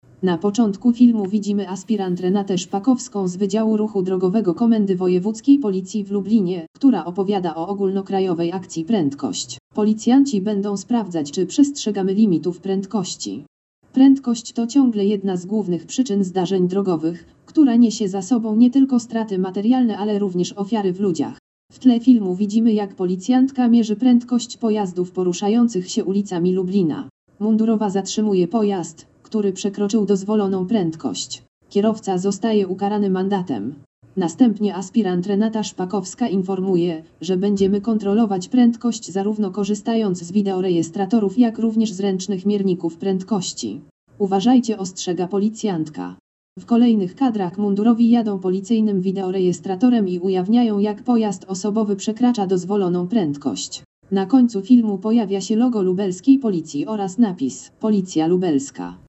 Nagranie audio Audiodeskrypcja filmu działania "Prędkość"